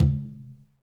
Tumba-HitN_v2_rr1_Sum.wav